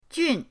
jun4.mp3